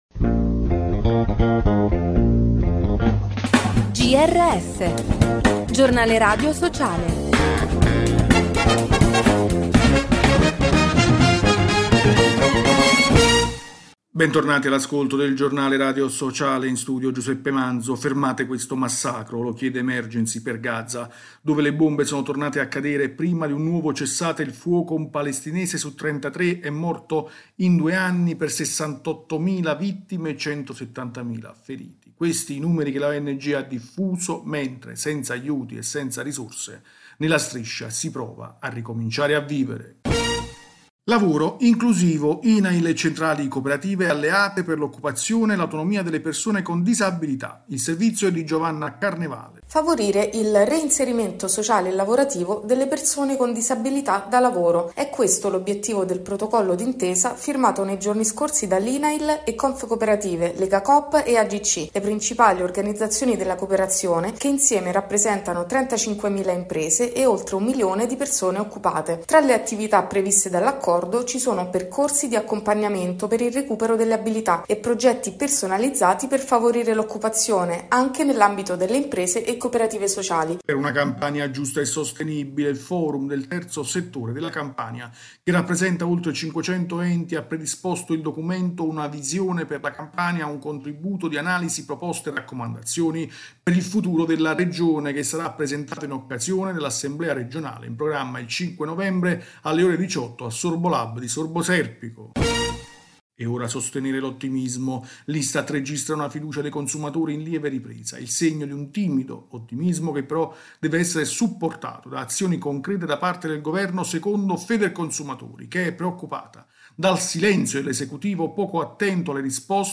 Bentornati all’ascolto del Giornale radio sociale.